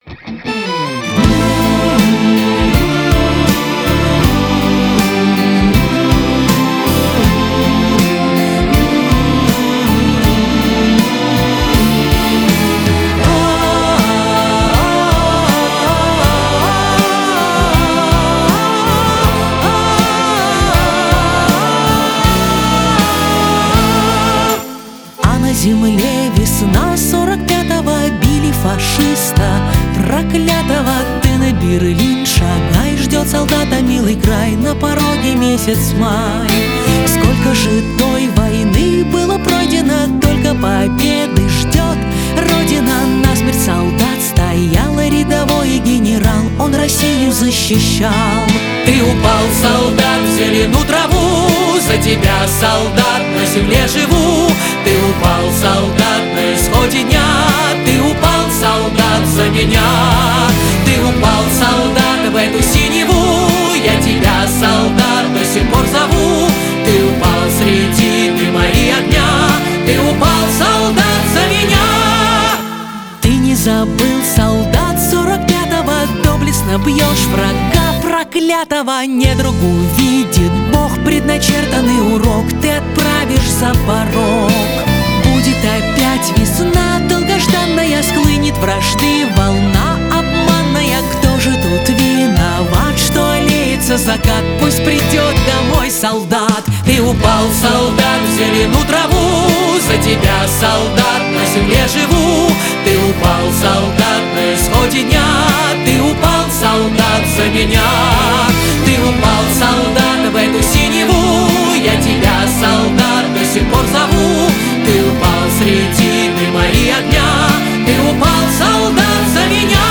• Категория: Детские песни
народный мотив